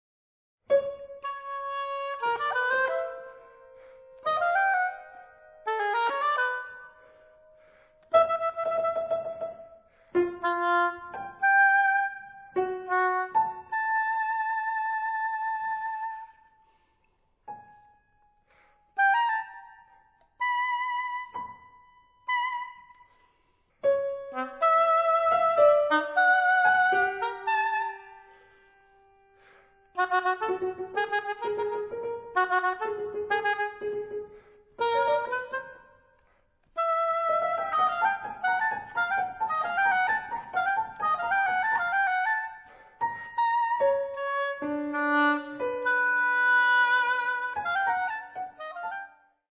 (oboe, cor anglais and piano)